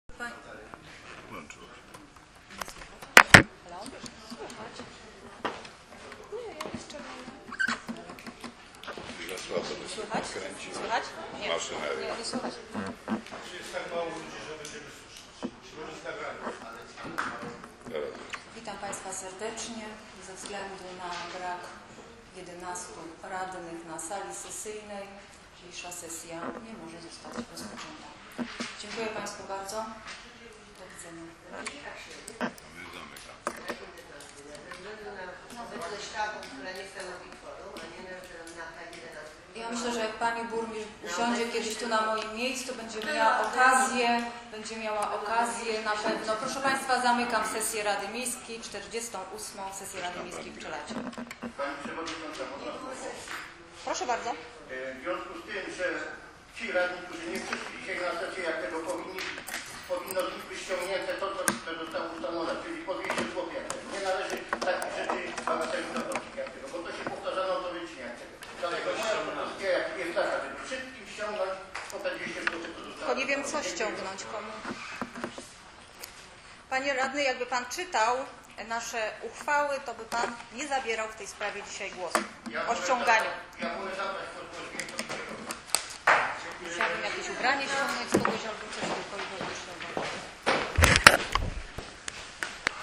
plik dźwiękowy z sesji w dniu 17 kwiecień 2013r.